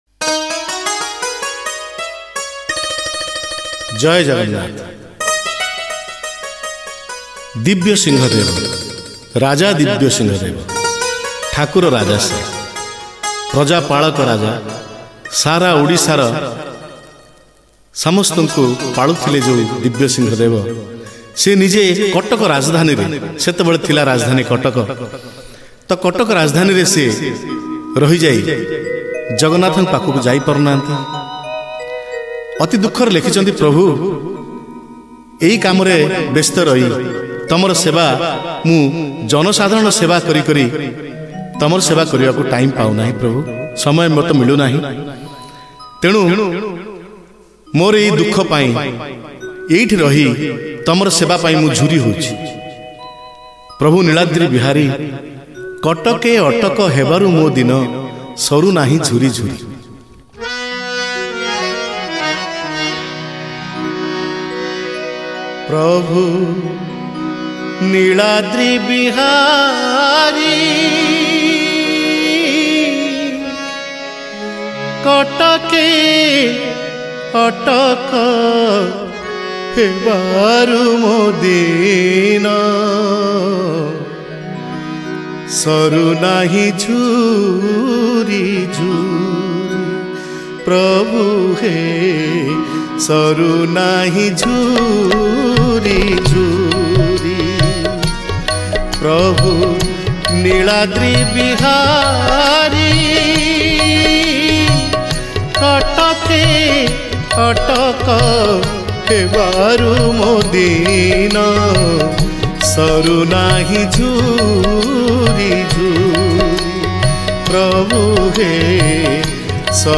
Odia Bhajan Song A-Z